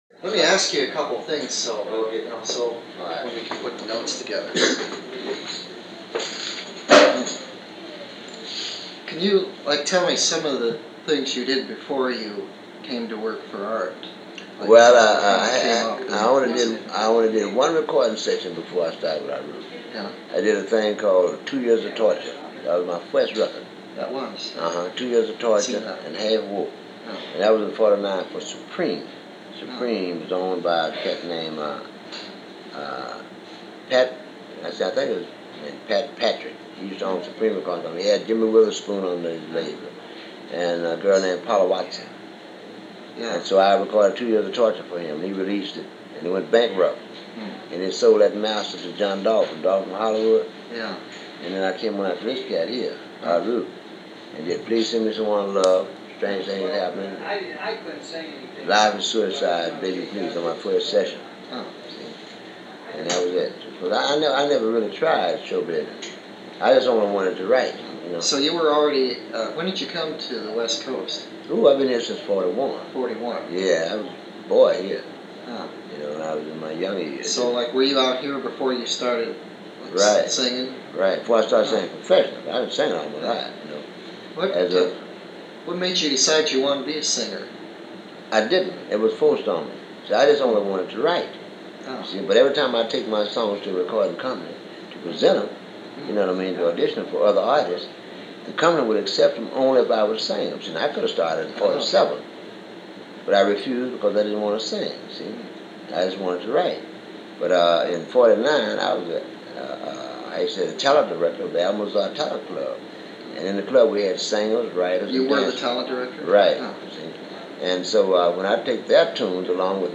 A Few Words From Percy Mayfield - 1969 Intervew with Art Rupe and Barry Hansen - May 13, 1969 - Specialty Records Office -Past Daily Weekend Pop Chronicles
Conducting the interview is Barry Hansen, best known as Doctor Demento who was working at Specialty at the time. Also popping in for a few questions and offering his insights is Art Rupe, who was the owner and visionary behind the success of Specialty and one of the key figures in the history of Rock n’ Roll.